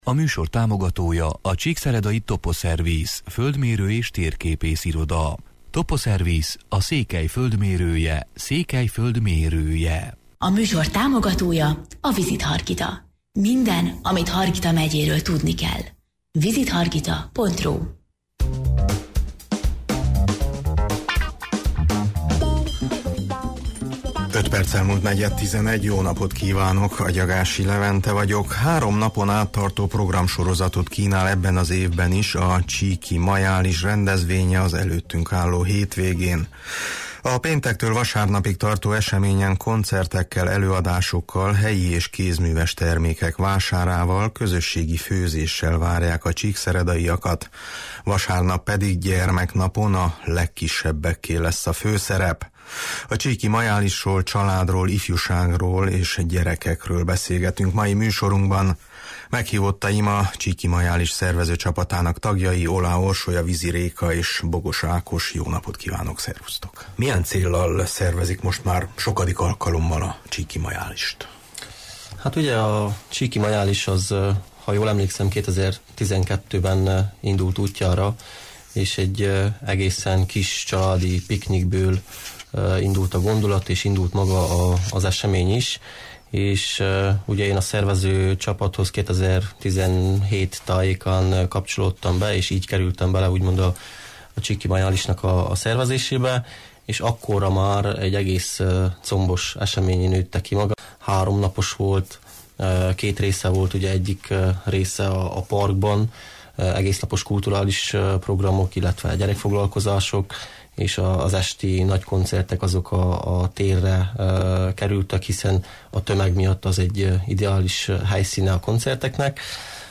A csíki majálisról, családról, ifjúságról és gyerekekről beszélgetünk mai műsorunkban.